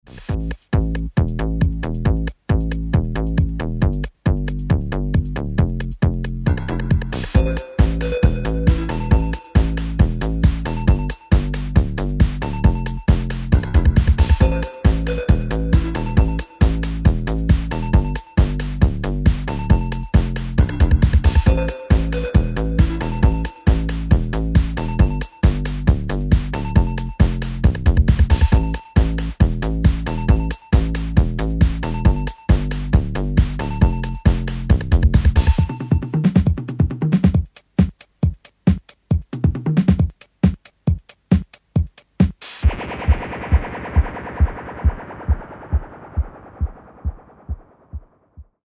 analoge Keyboards, Synthies und Effektgeräte